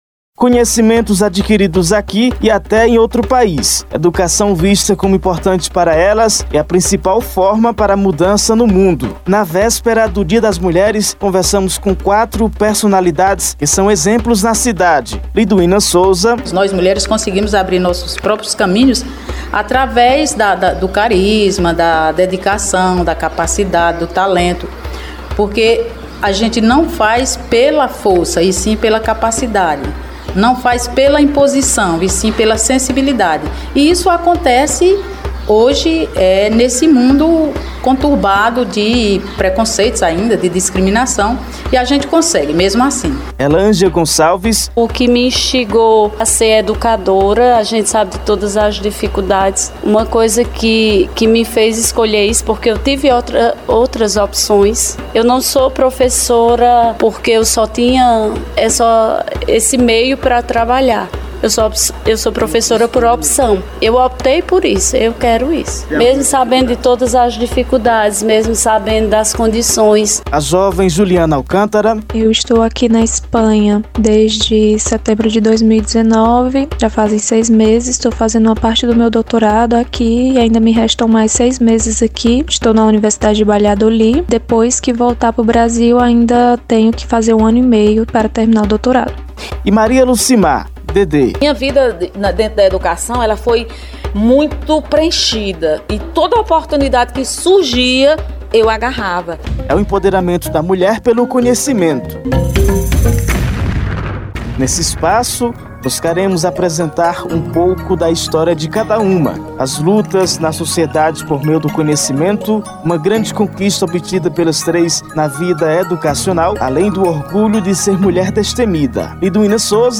Confira nosso podcast em homenagem as mulheres pelo seu dia, o conteúdo foi produzido neste sábado, 07, e exibido pela FM Cultura de Várzea Alegre: